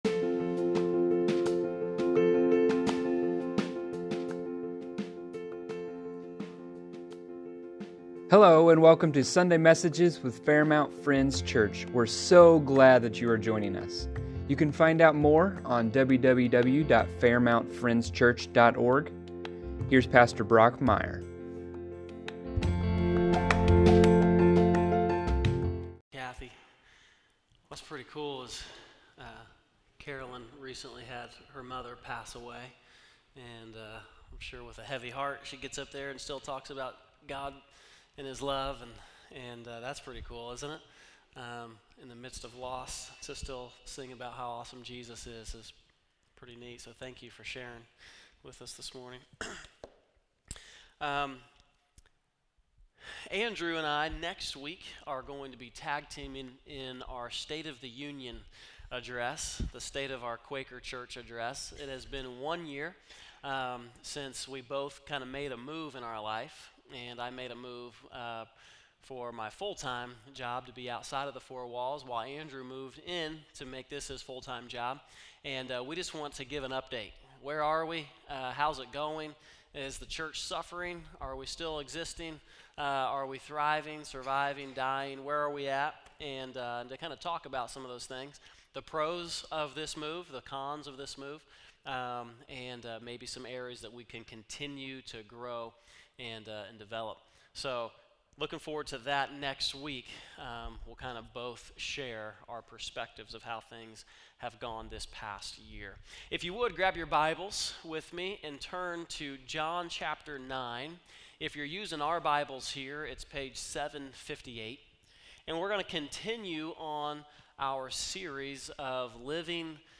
Sunday Messages | Fairmount Friends Church